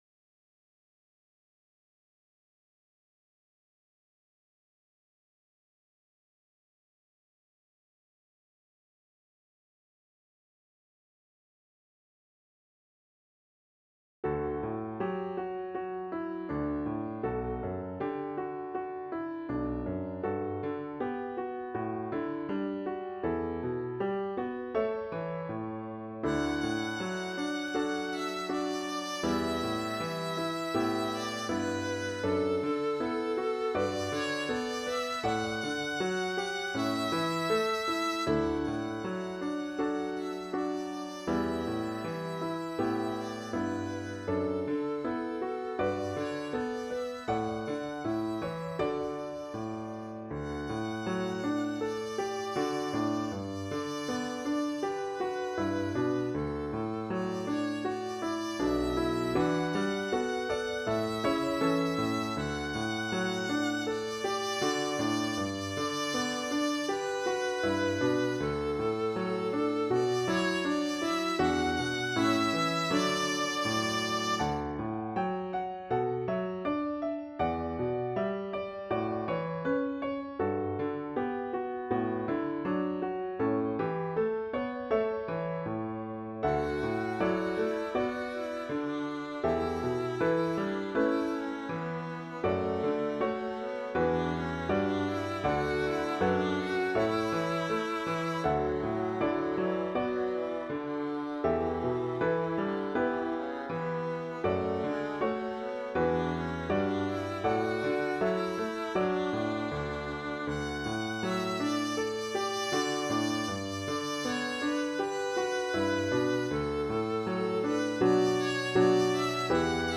Intermediate Instrumental Solo with Piano Accompaniment.
Christian, Gospel, Sacred.
gentle, meditative mood.